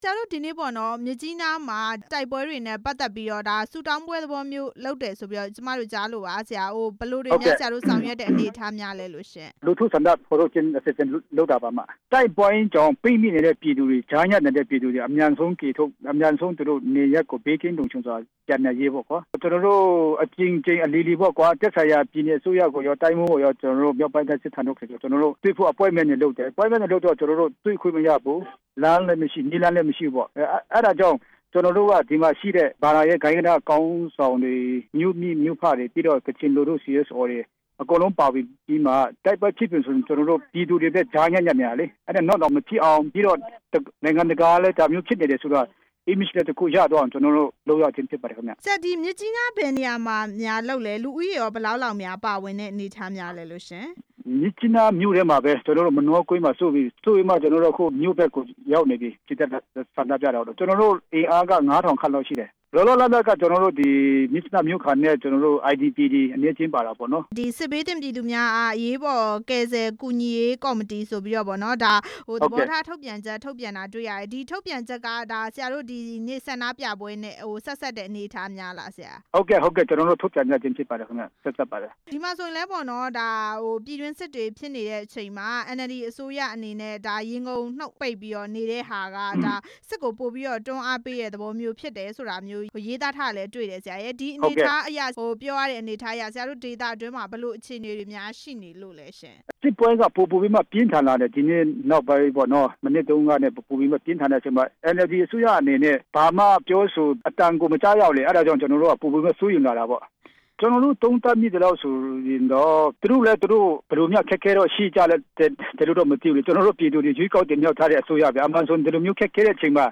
ကချင် စစ်ဘေး ဒုက္ခသည်တွေ နေရပ်ပြန်နိုင်ရေး ဆန္ဒပြပွဲ အကြောင်း ဆက်သွယ် မေးမြန်းချက်